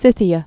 (sĭthē-ə, sĭth-)